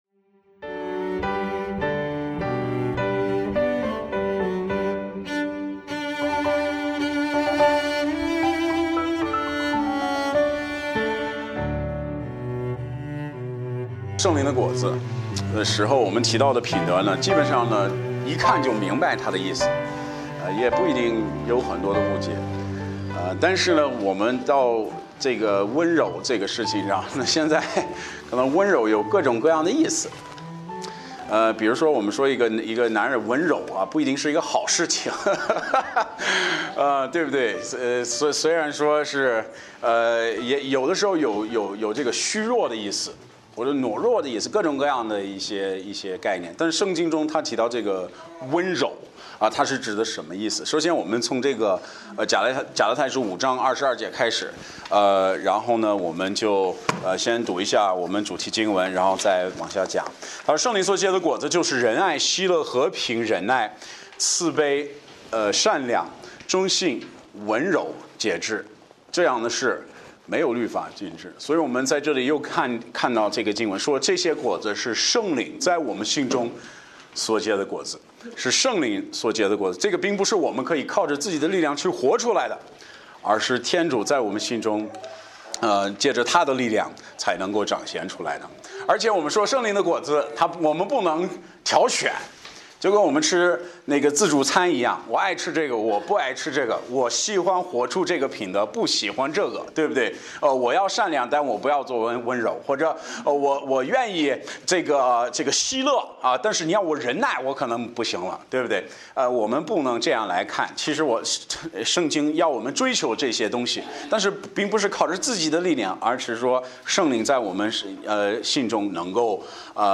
圣灵的果子- 温柔 – 真柱浸信教会